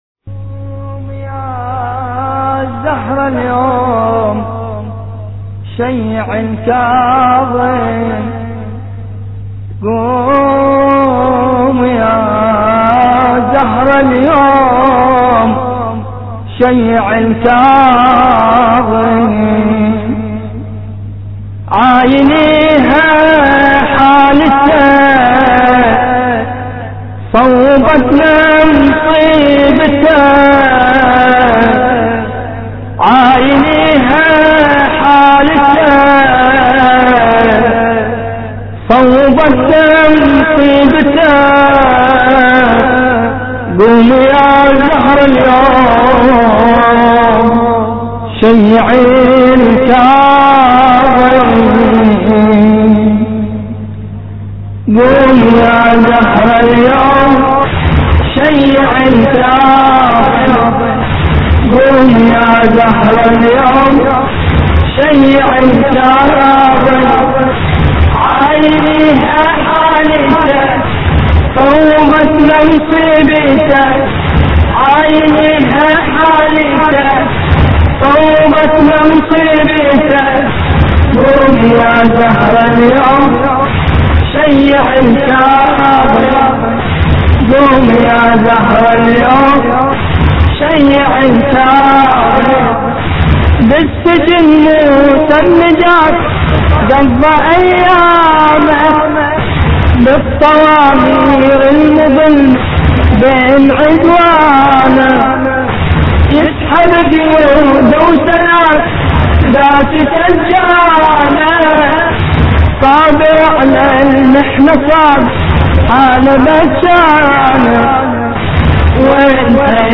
مراثي الامام الكاظم (ع)